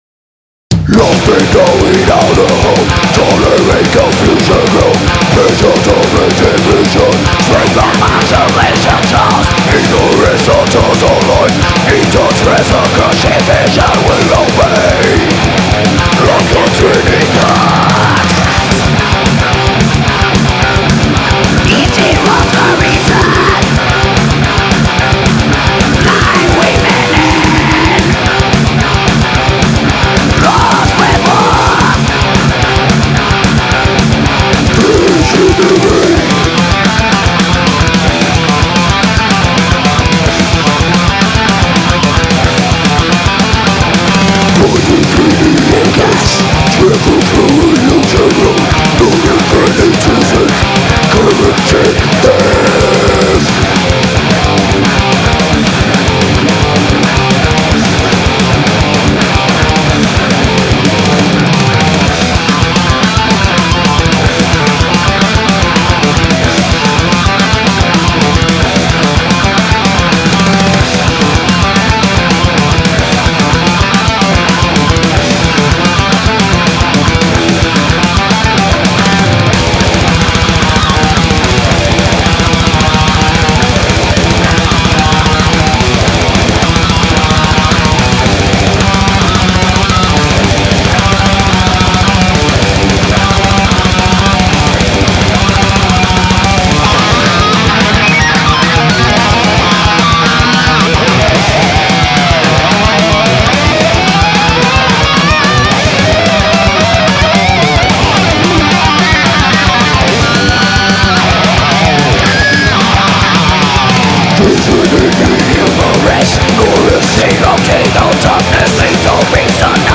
Extreme metal that hits harder, faster, and heavier.